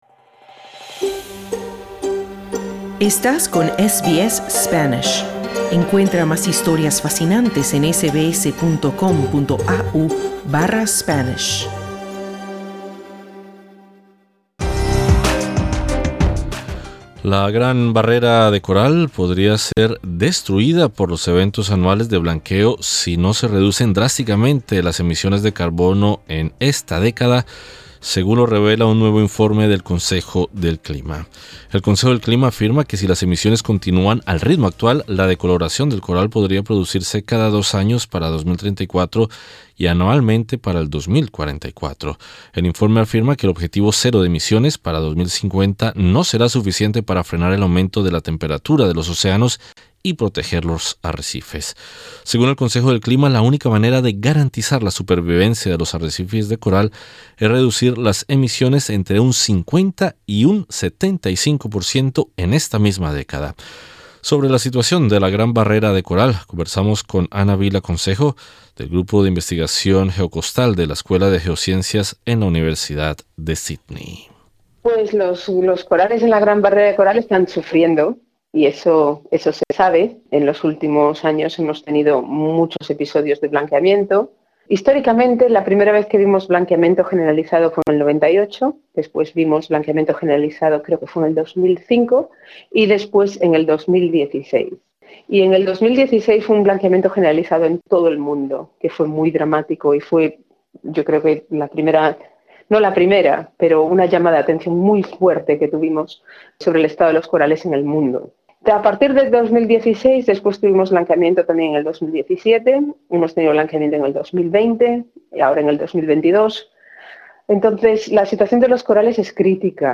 La Gran Barrera de Coral podría ser destruida por los eventos anuales de blanqueo si no se reducen drásticamente las emisiones de carbono en esta década, según un nuevo informe del Consejo del Clima. Conversamos con dos científicos líderes en cambio climático y la barrera de coral.